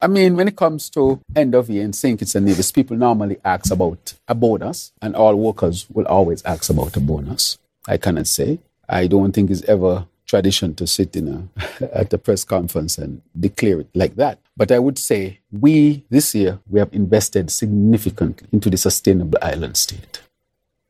Will civil servants in St. Kitts & Nevis receive a “double salary”? That question was posed to Prime Minister and Minister of Finance, Hon. Dr. Terrance Drew.